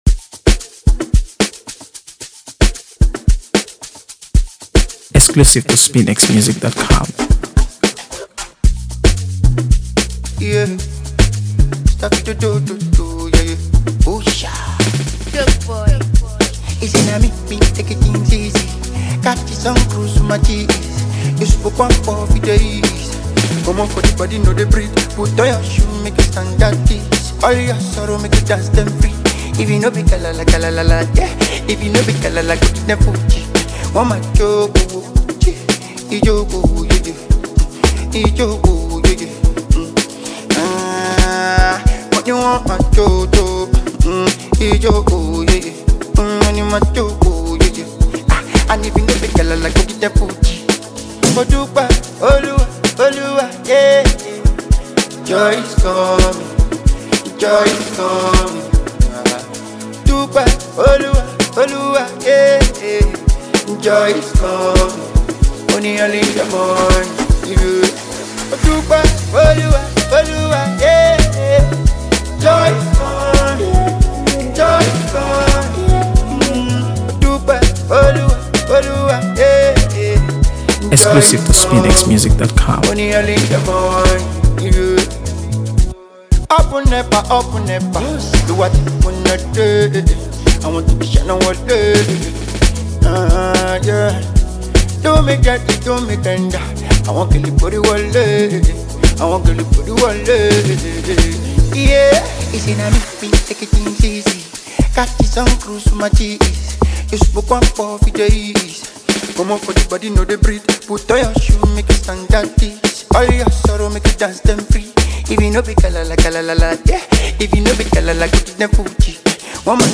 AfroBeats | AfroBeats songs
A seamless blend of Afrobeat and contemporary pop